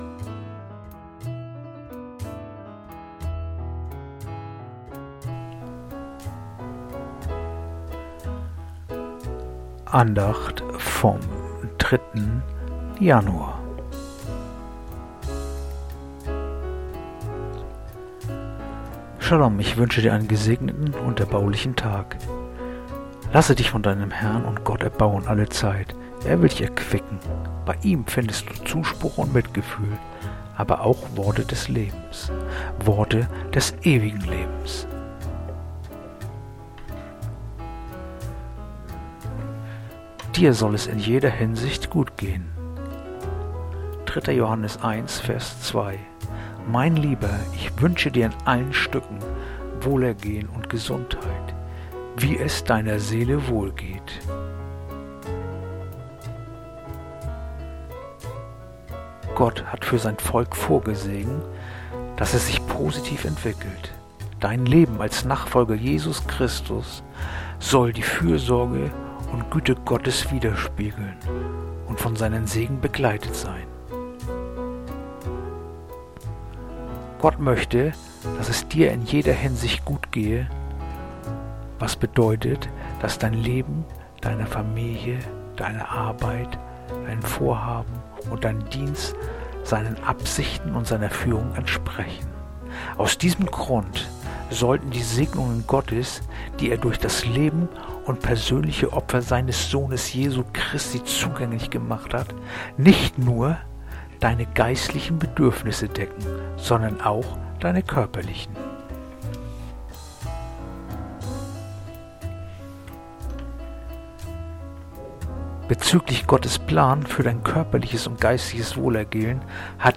heutige akustische Andacht
Andacht-vom-03-Januar-3-Johannes-1-2.mp3